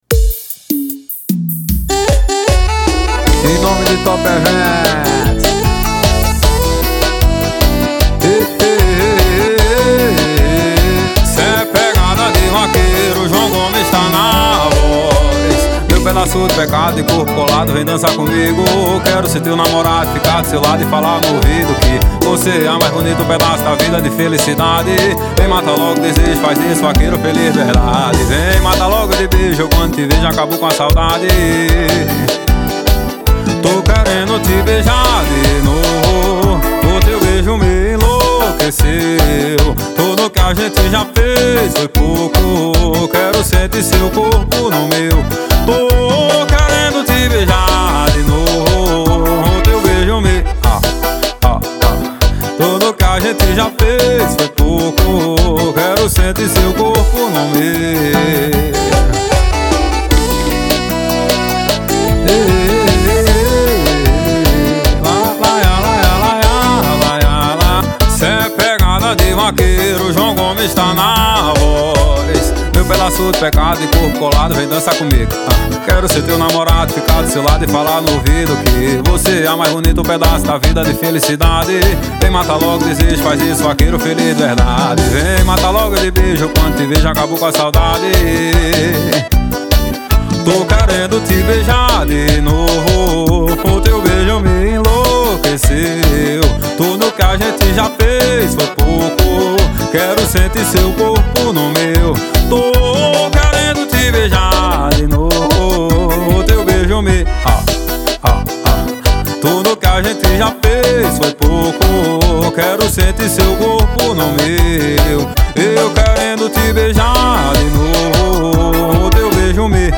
2024-02-14 17:56:18 Gênero: Forró Views